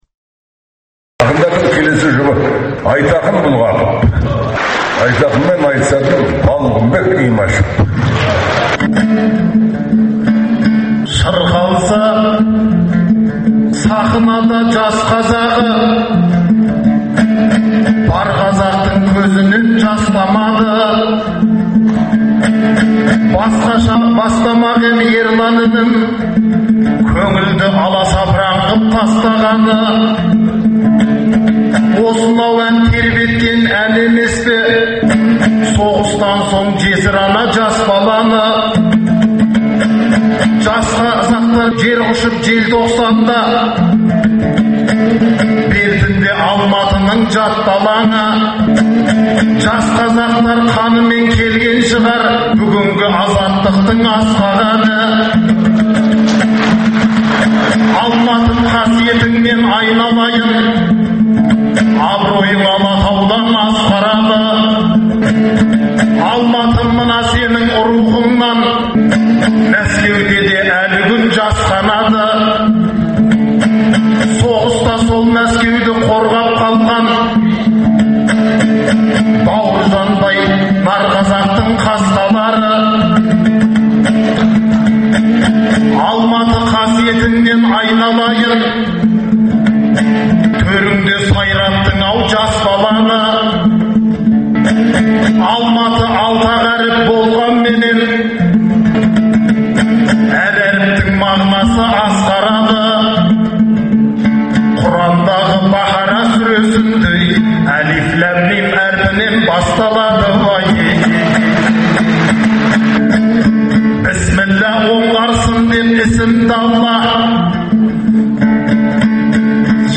Айтыстан үзінділер беріледі, ақындар айтысының үздік нұсқалары тыңдарменға сол қалпында ұсынылып отырады.